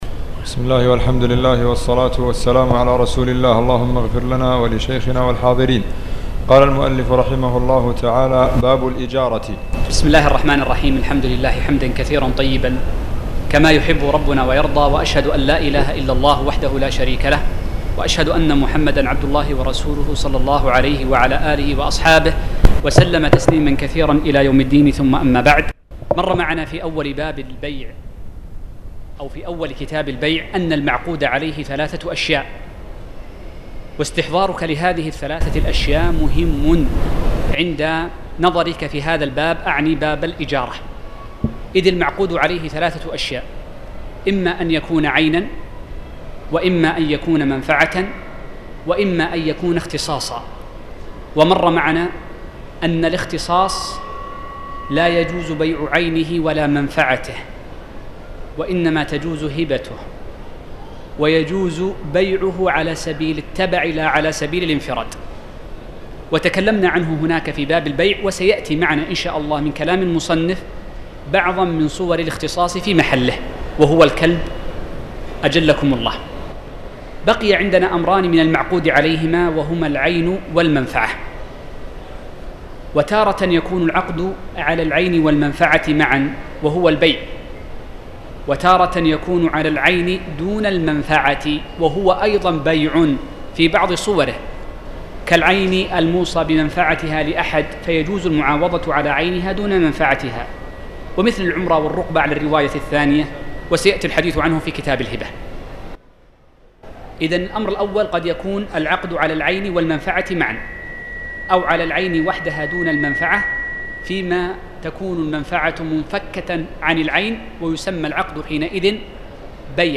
تاريخ النشر ٥ رجب ١٤٣٨ هـ المكان: المسجد الحرام الشيخ